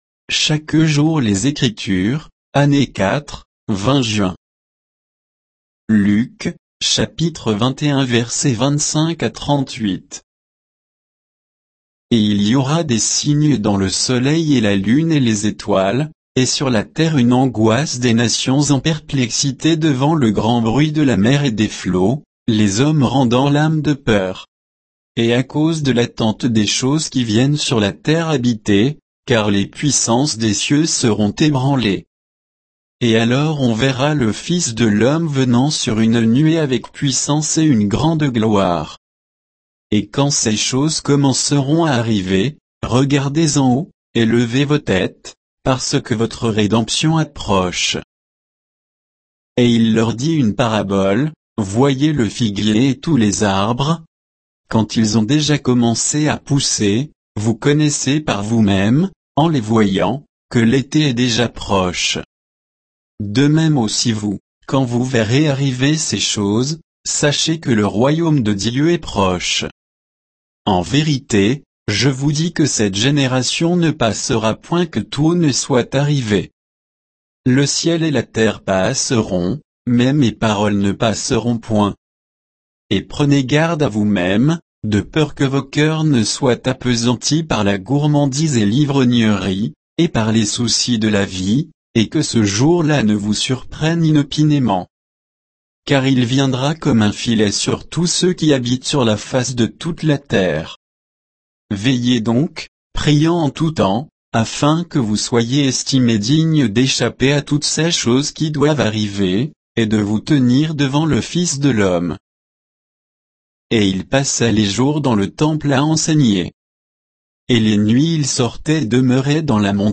Méditation quoditienne de Chaque jour les Écritures sur Luc 21